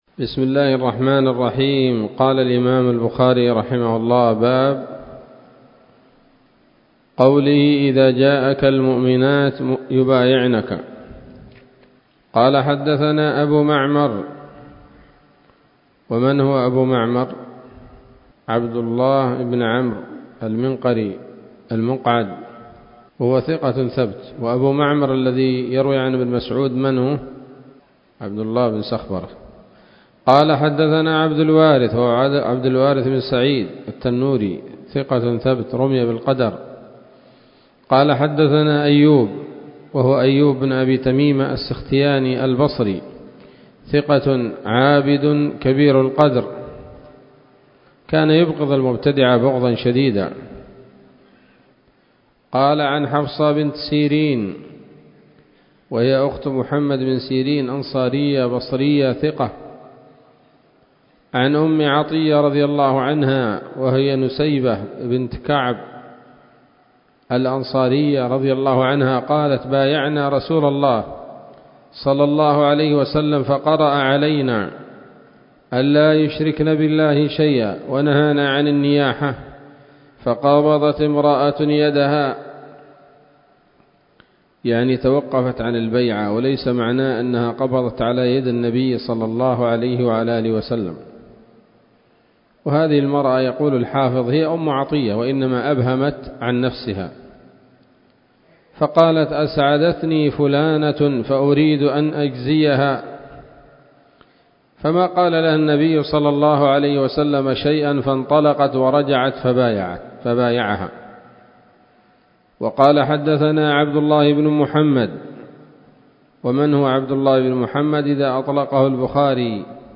الدرس الثامن والخمسون بعد المائتين من كتاب التفسير من صحيح الإمام البخاري